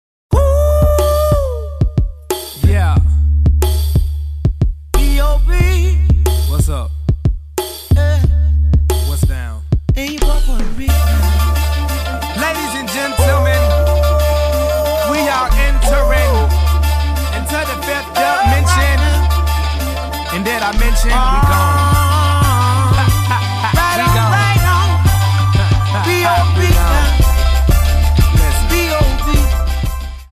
• Качество: 128, Stereo
мужской голос
Хип-хоп
спокойные
красивая мелодия